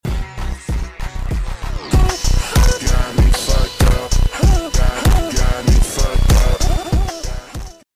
performance edit